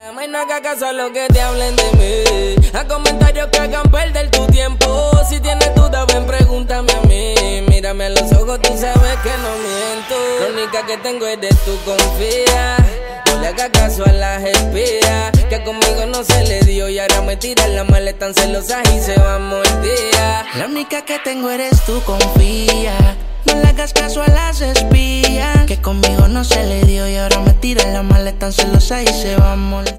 Siempre los últimos tonos de Reguetón